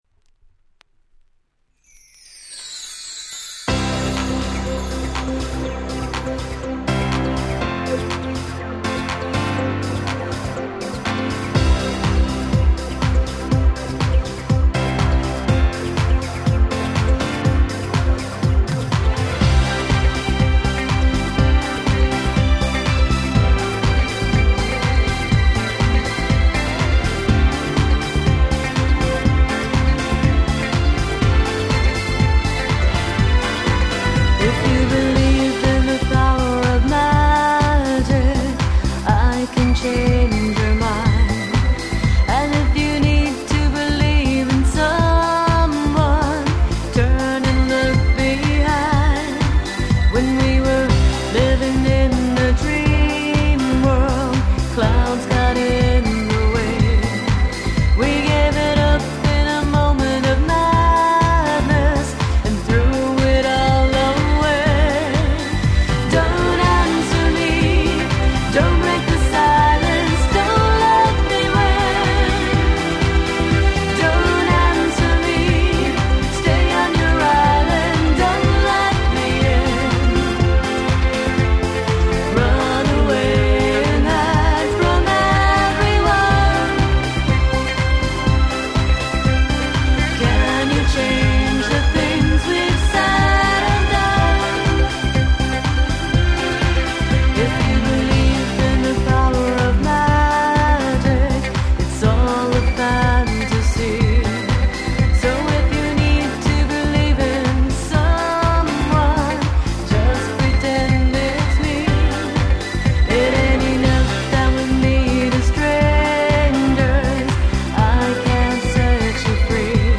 最高にキャッチーで幸せな気分になれる事請け合いです！